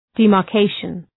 {,di:mɑ:r’keıʃən}